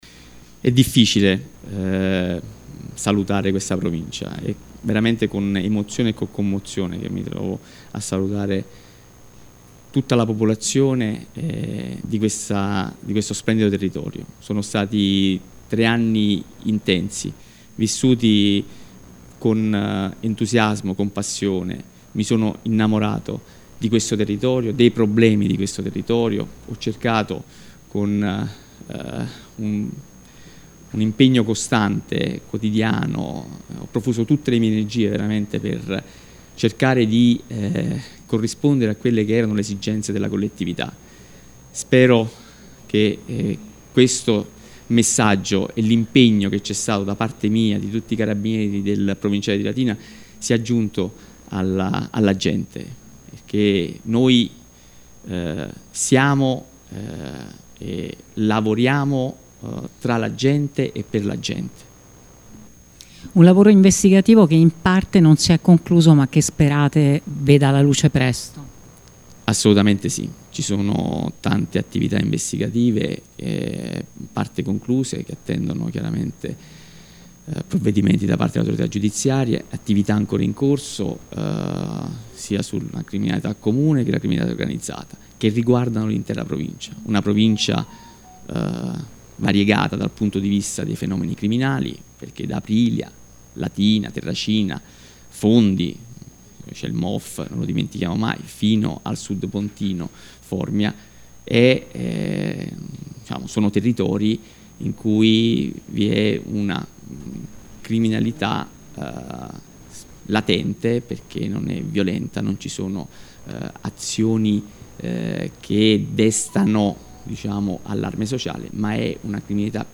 LATINA –  Saluta commosso il colonnello Lorenzo D’Aloia che oggi lascia ufficialmente il Comando provinciale dei carabinieri di Latina.
nel corso dell’incontro con i media, parole di ringraziamento sono state rivolte a tutte le istituzioni e all’intera squadra dei carabinieri “che lascio compatta e coesa”.
daloia-saluto-2023.mp3